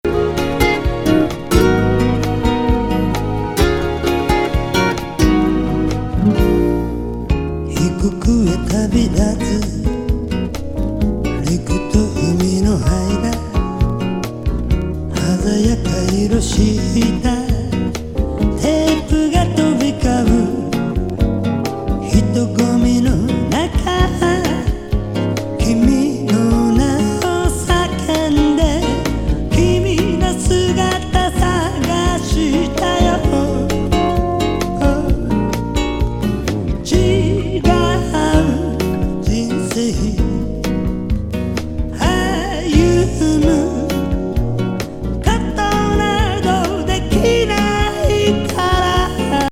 極上メロウ酒焼けボッサA面